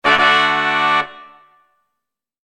Ta-da-sound.mp3